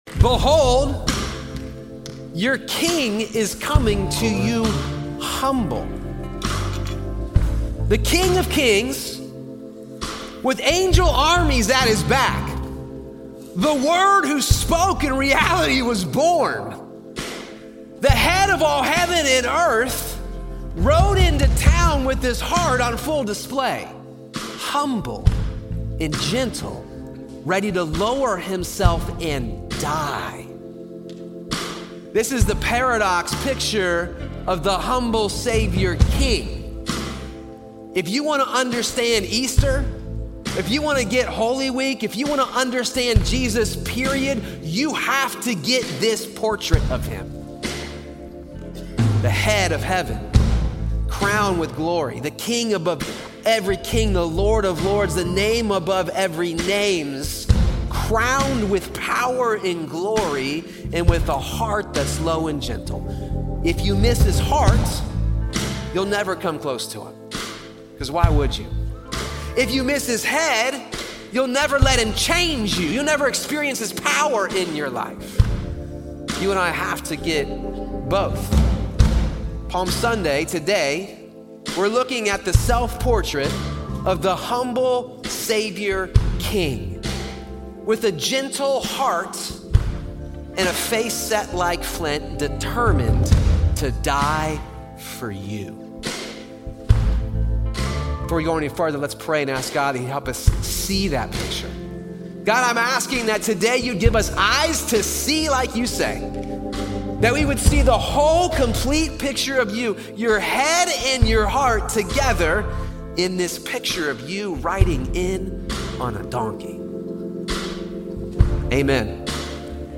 This week, we kick off Holy Week talking about the arrival of the King—and how He continues to come to us in ways we would never expect. Recorded live at Crossroads Church in Cincinnati, Ohio.